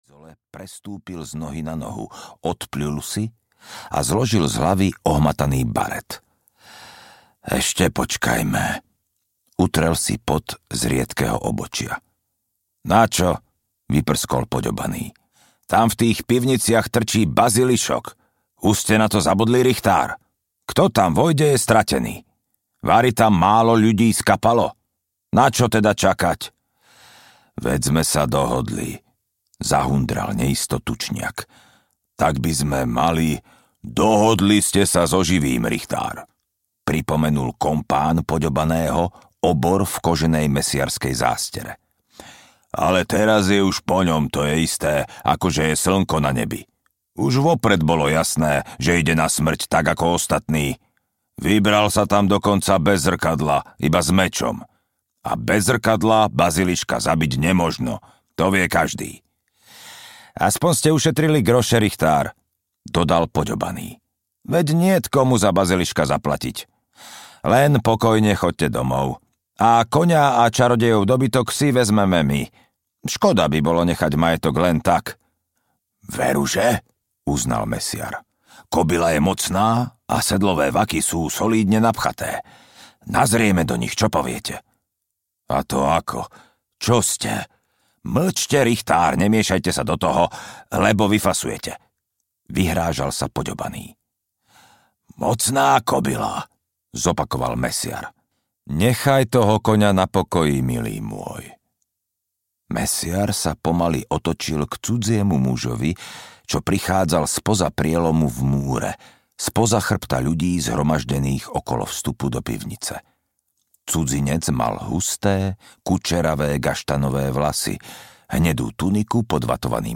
Zaklínač II: Meč osudu audiokniha
Ukázka z knihy